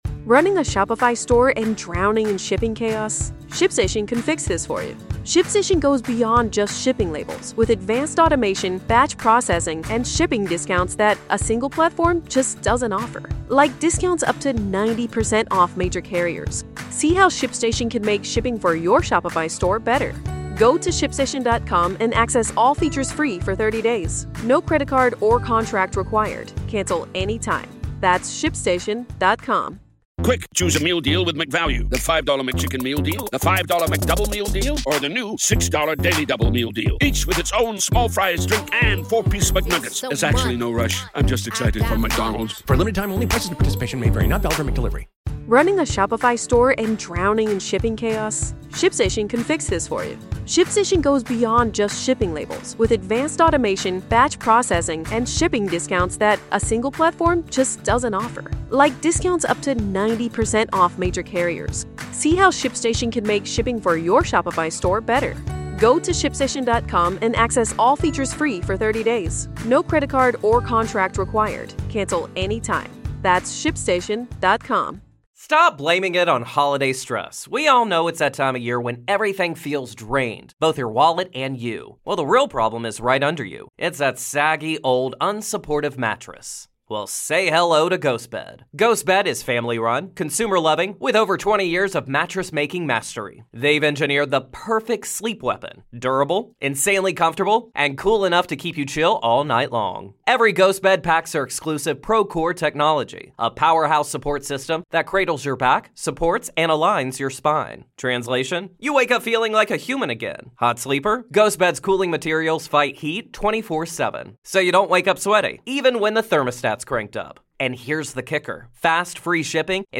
Final Destination: Bloodlines Interview